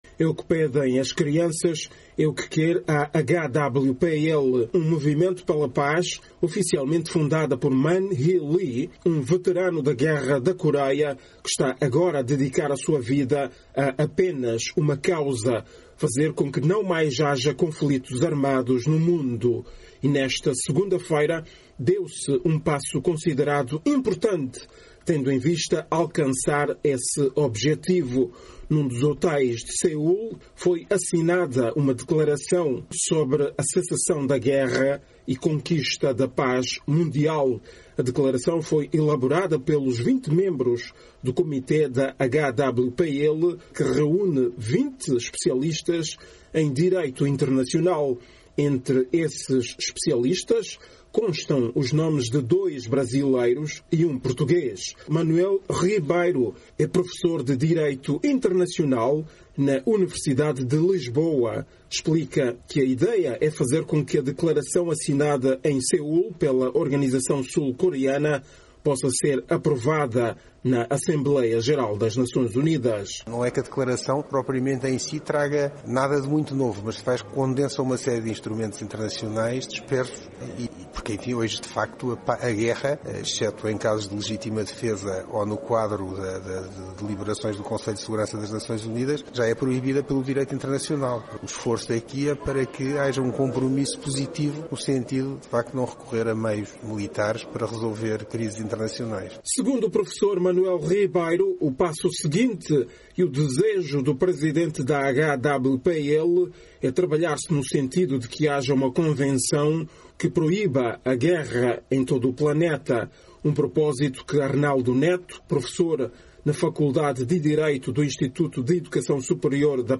A cerimónia solene de assinatura da declaração sobre a cessação da guerra e a conquista da paz mundial teve lugar esta segunda-feira, 14, em Seul, capital da Coreia do Sul.
Crianças sul-coreanas reproduzindo uma música célebre do também célebre cantor norte-americano e já falecido Michael Jackson.